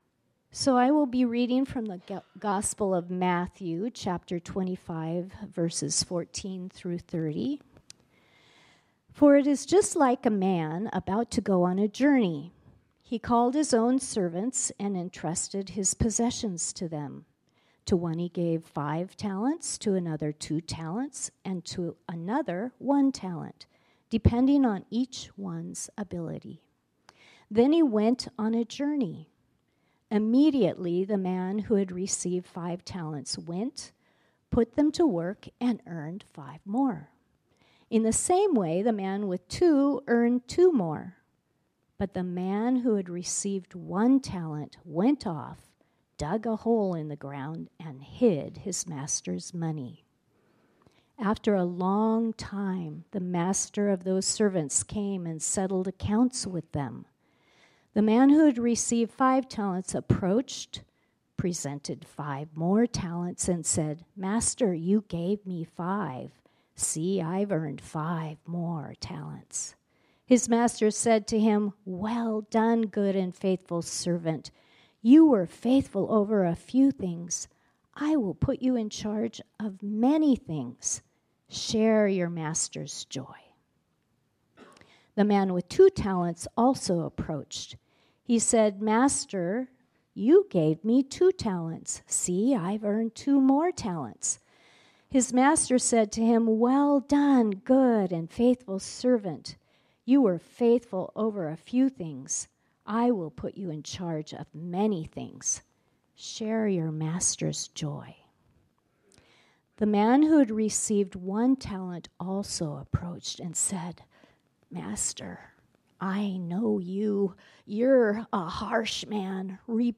This sermon was originally preached on Sunday, October 27, 2024.